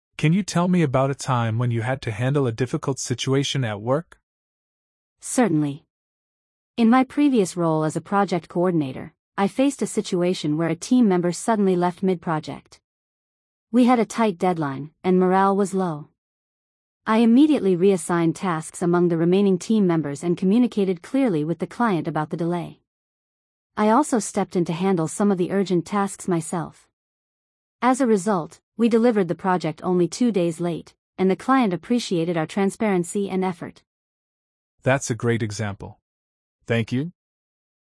🤝 Interviewer asks about a past work challenge.